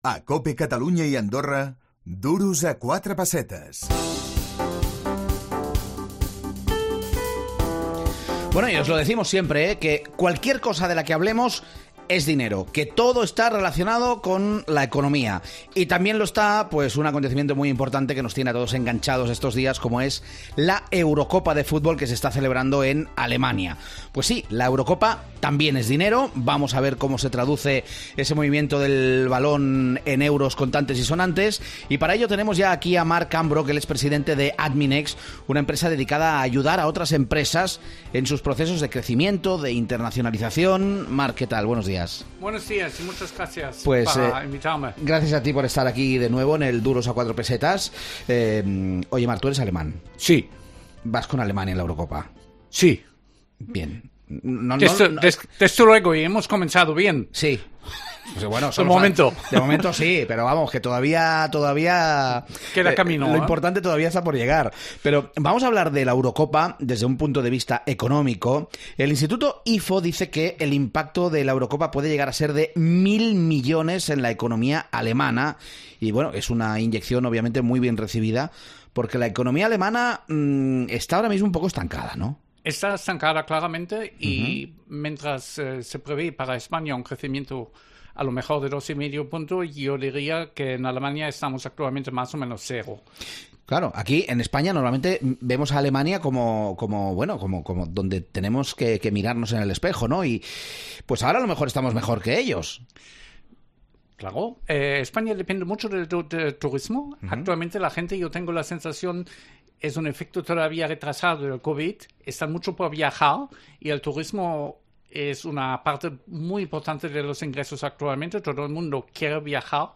L'actualitat econòmica, desde diferents angles, al “Duros a 4 pessetes”, el teu programa econòmic de capçalera. Analitzem totes aquelles notícies que poden afectar a la teva butxaca.